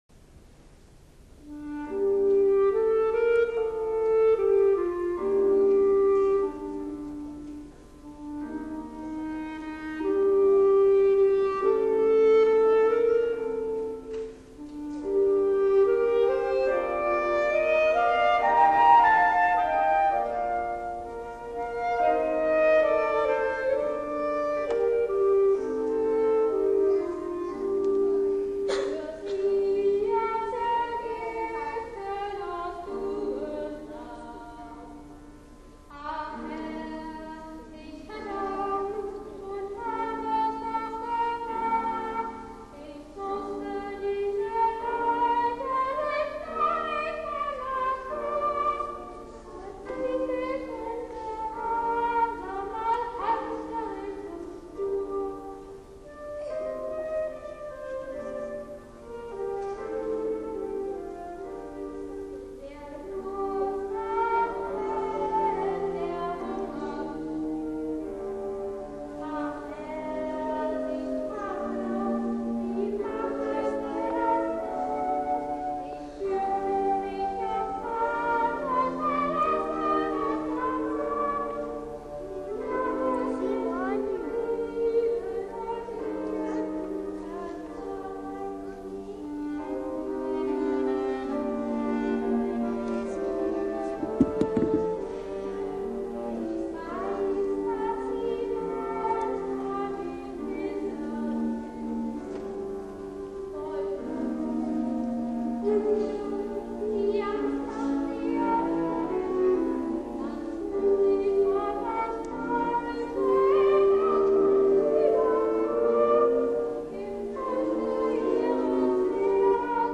Das Kindermusical basiert auf
Moseslied von der Uraufführung in Stromberg
Instrumente: Flöte, Violine, Klarinette, Klavier